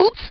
Voice clip from PaRappa the Rapper and Um Jammer Lammy.
File File history File usage PTR_Used_OOps.wav  (WAV audio file, length 0.3 s, 176 kbps overall) Summary This file is an audio rip from a(n) PlayStation game.